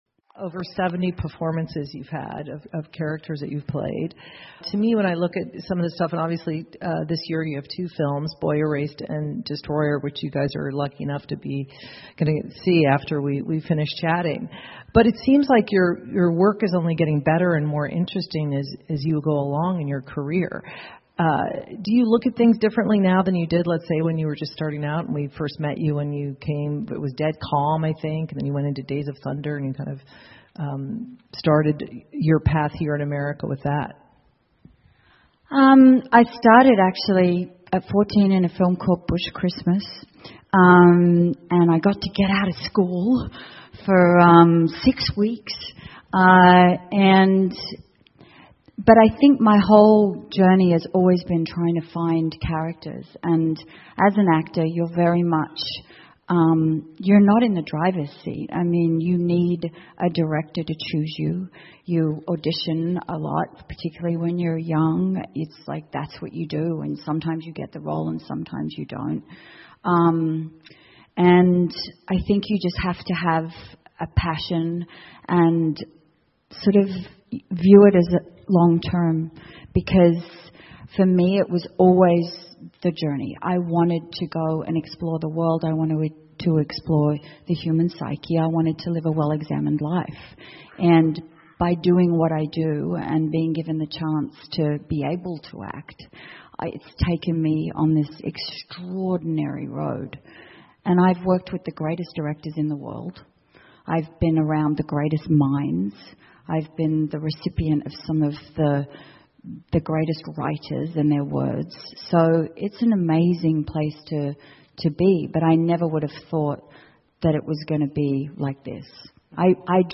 英文演讲录 妮可·基德曼：我的演艺生涯(1) 听力文件下载—在线英语听力室